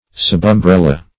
Search Result for " subumbrella" : The Collaborative International Dictionary of English v.0.48: Subumbrella \Sub`um*brel"la\, n. (Zool.)